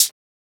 Closed Hats
edm-hihat-24.wav